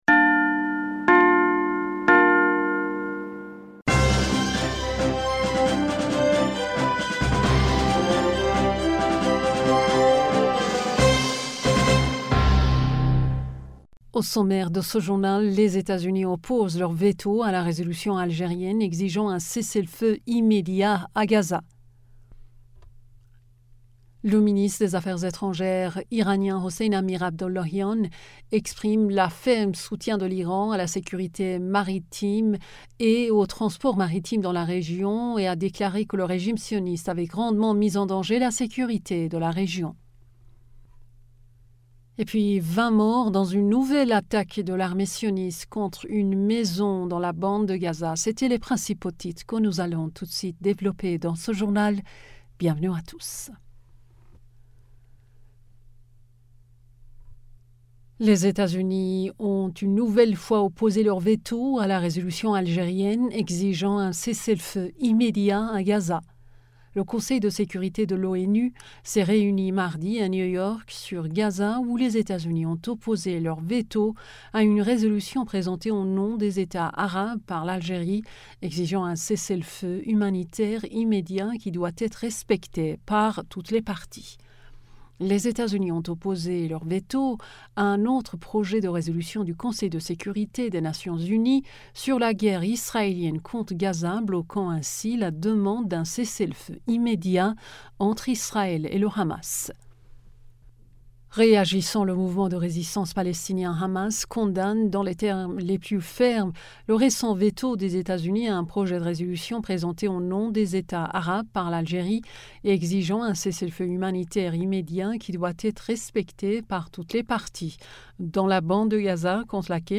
Bulletin d'information du 21 Fevrier 2024